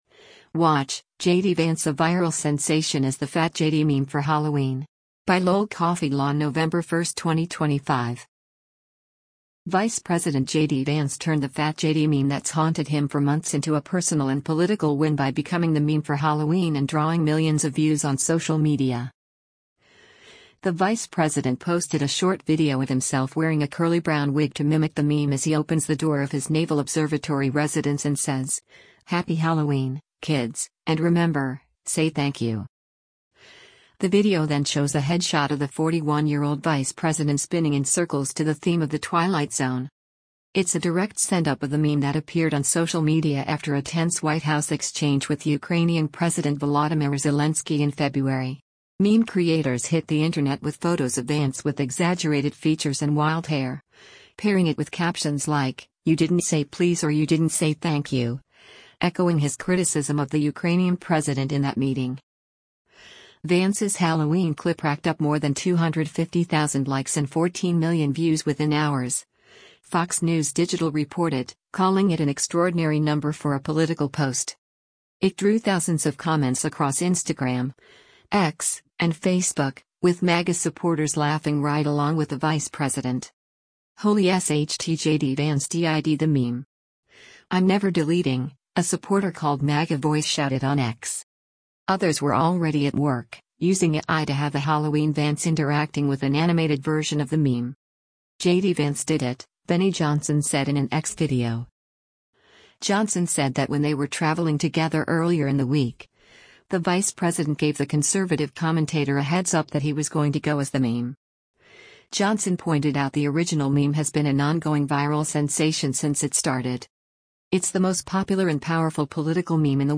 The vice president posted a short video of himself wearing a curly brown wig to mimic the meme as he opens the door of his Naval Observatory residence and says, “Happy Halloween, kids — and remember, say thank you.”
The video then shows a head shot of the 41-year-old vice president spinning in circles to the theme of the Twilight Zone.